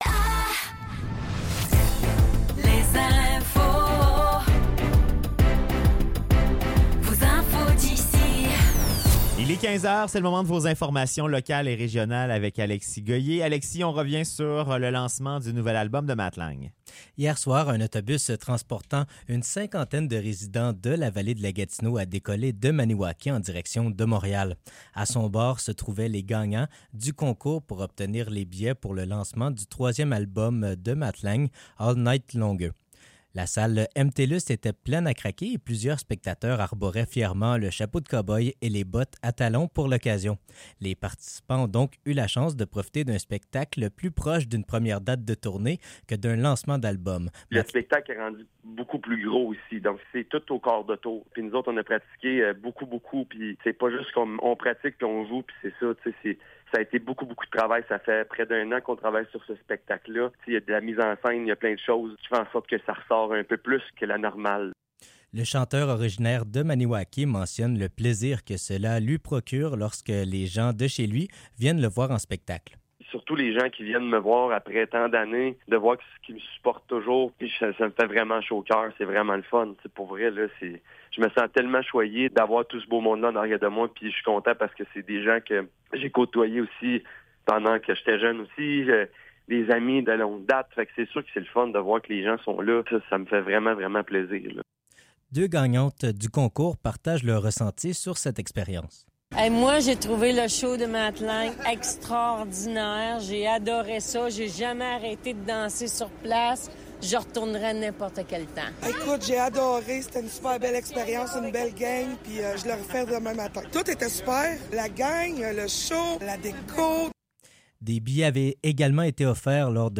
Nouvelles locales - 10 mai 2024 - 15 h